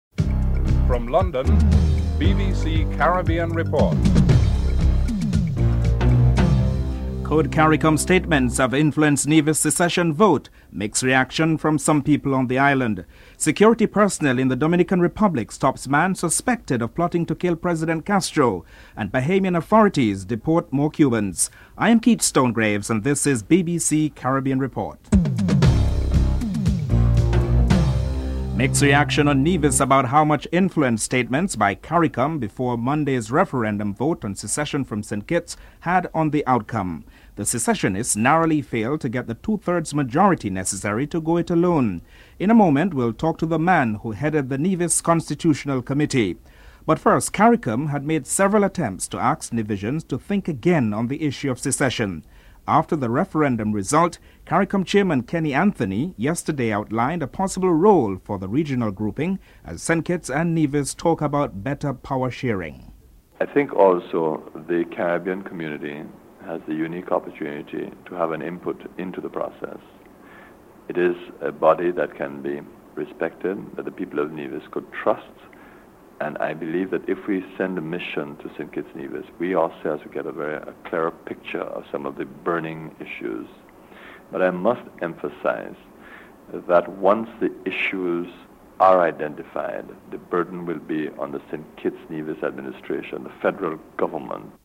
1. Headlines (00:00-00:27)
Barbados' Ambassador to Brussels Michael King is interviewed (09:39-11:31)